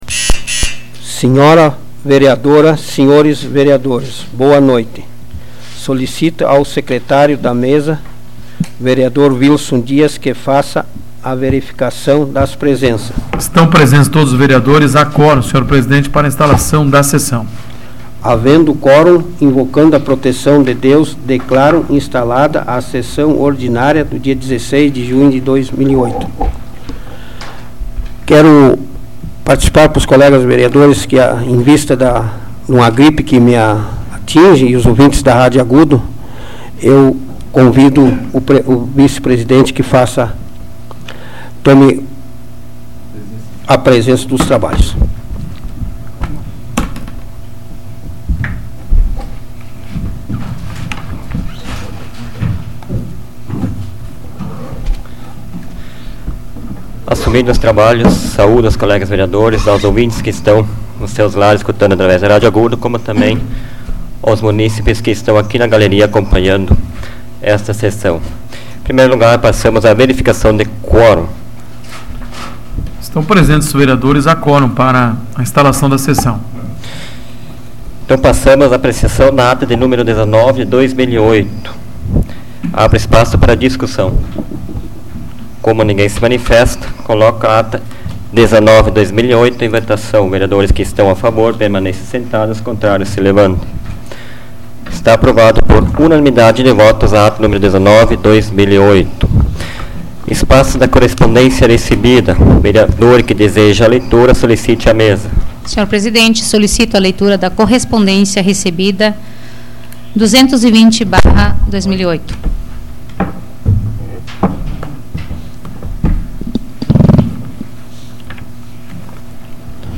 Áudio da 126ª Sessão Plenária Ordinária da 12ª Legislatura, de 16 de junho de 2008